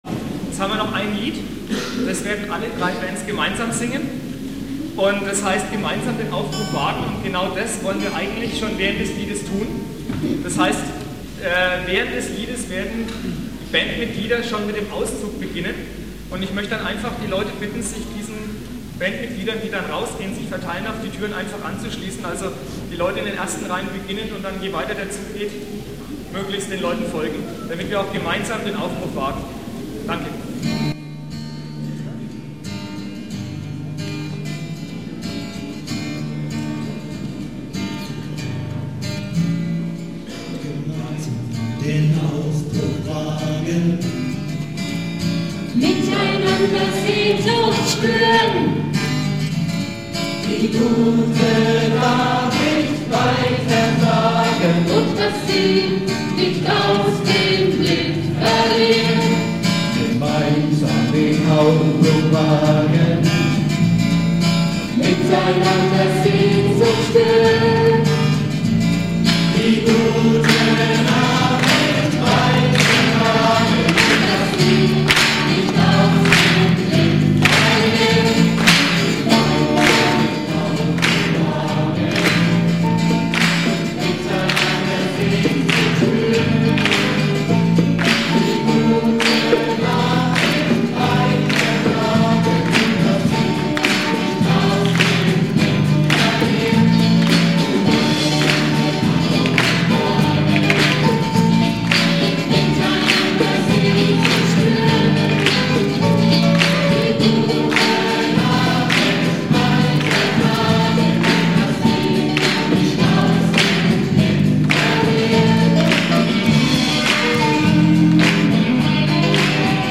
alle Aufnahmen sind "live" in der Kirche mitgeschnitten und deshalb keine CD-Qualität